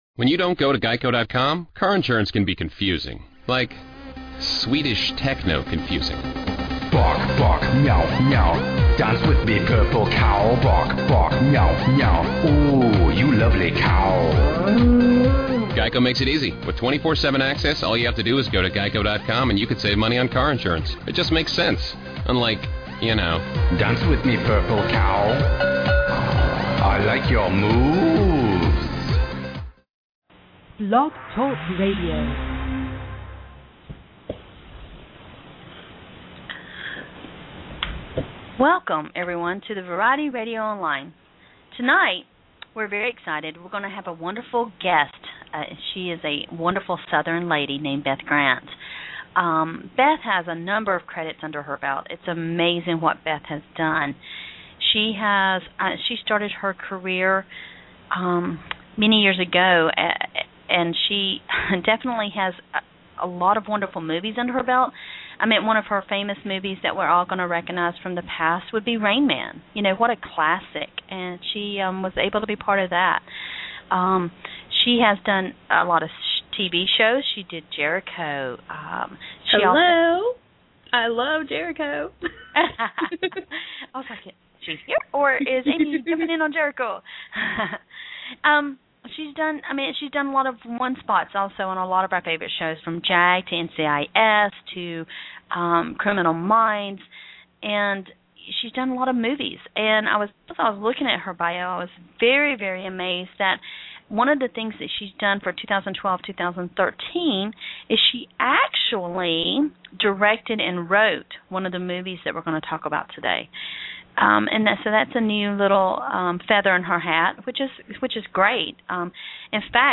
Beth Grant - Interview